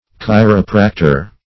chiropractor.mp3